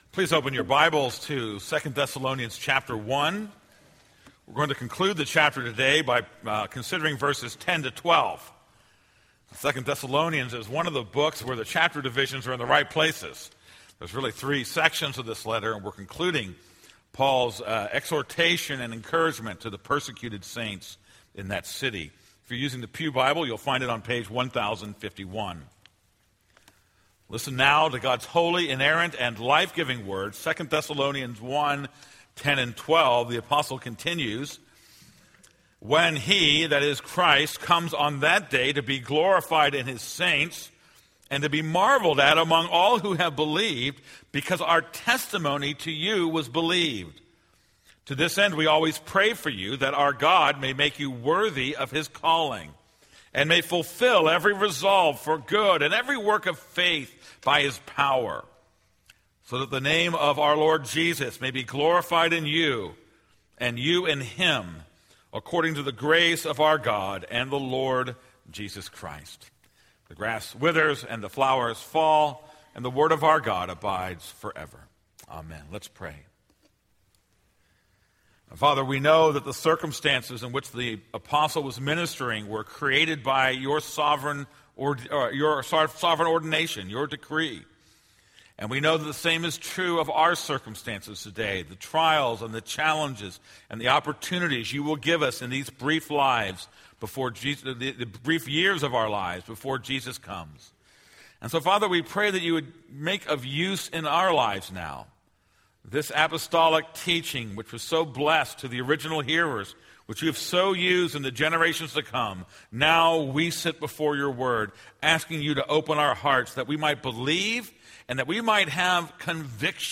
This is a sermon on 2 Thessalonians 1:10-12.